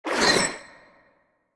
Sfx_creature_brinewing_ready_01.ogg